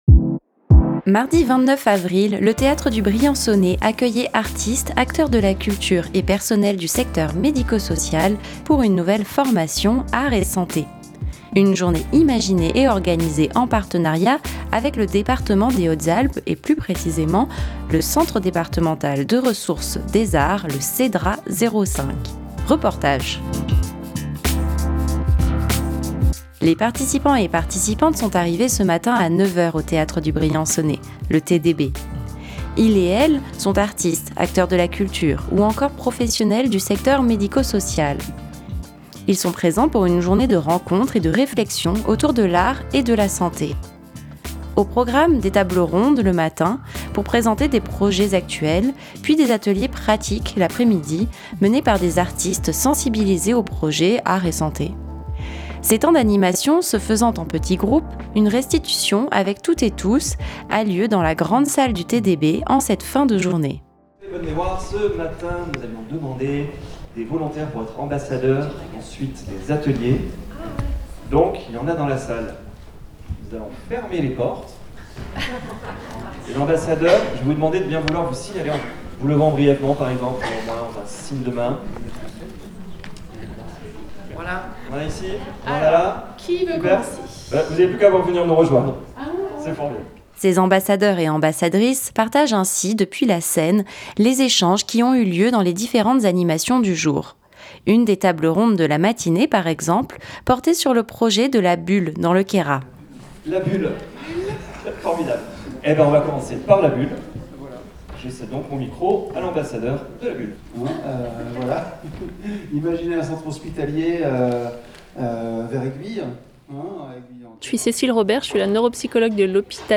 Reportage.